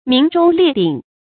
鸣钟列鼎 míng zhōng liè dǐng
鸣钟列鼎发音